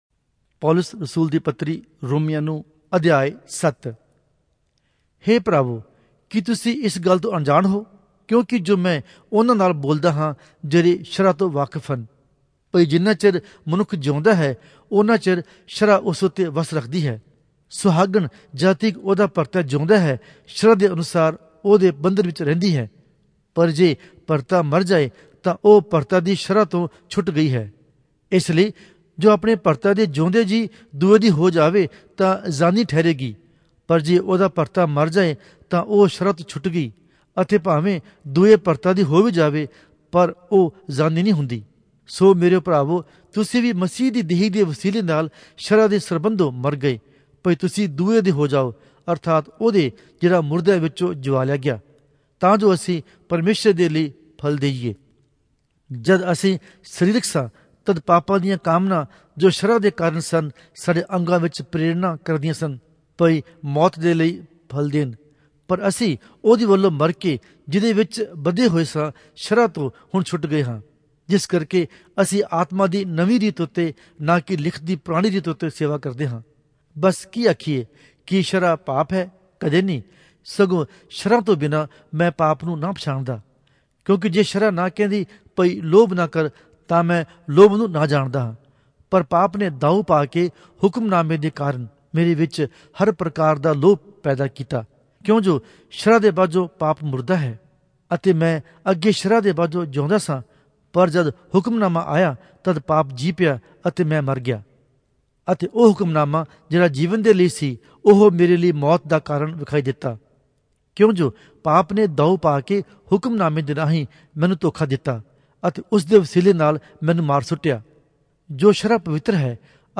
Punjabi Audio Bible - Romans 6 in Asv bible version